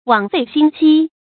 注音：ㄨㄤˇ ㄈㄟˋ ㄒㄧㄣ ㄐㄧ
枉費心機的讀法